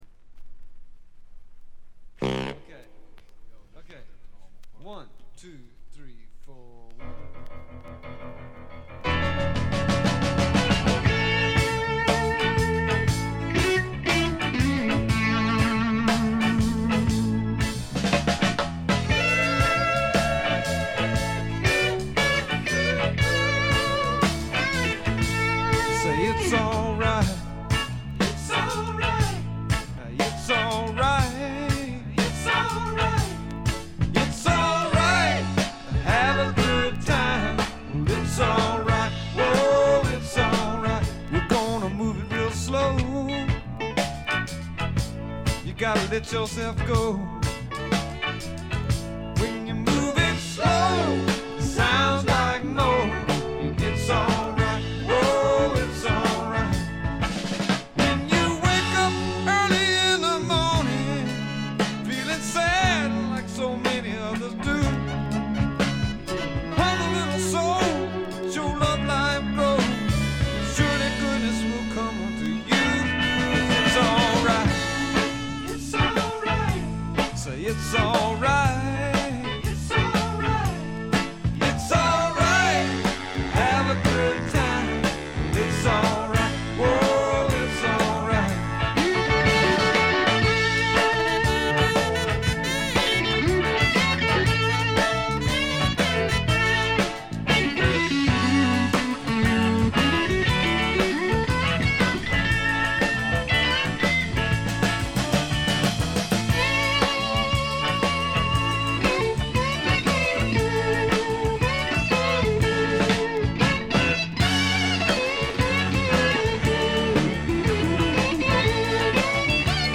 ところどころでチリプチ、プツ音少し。
フリーソウル的なポップ感覚が心地よいです。
試聴曲は現品からの取り込み音源です。